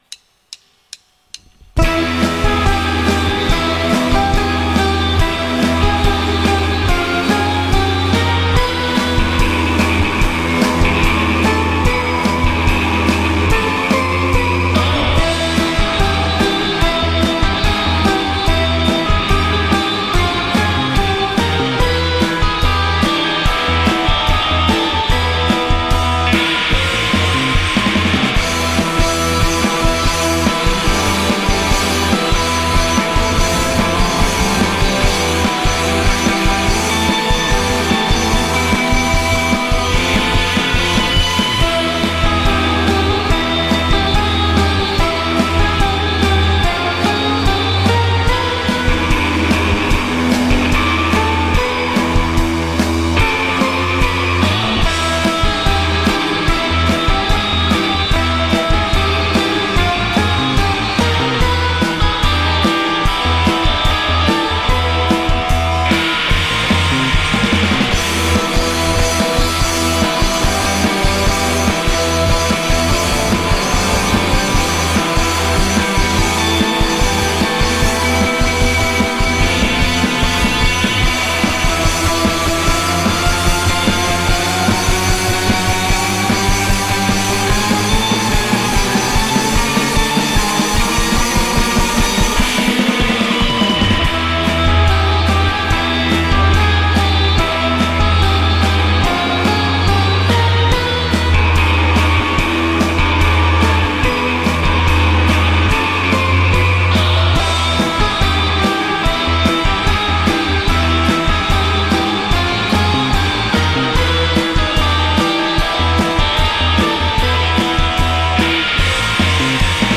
recorded live
instrumental space-surf band